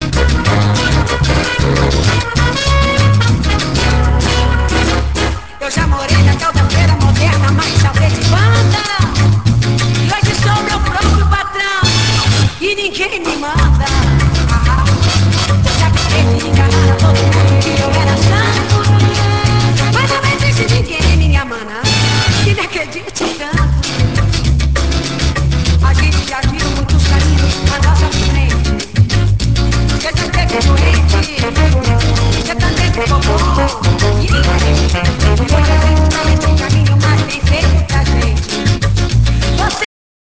avec sa voix de contraalto au discours désinvolte